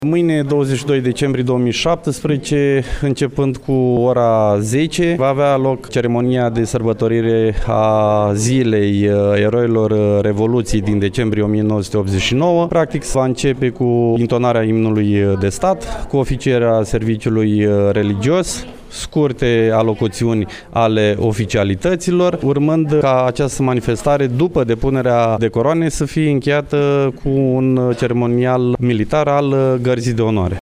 Programul manifestărilor a fost anunţat de prefectul judeţului, Marian Şerbescu.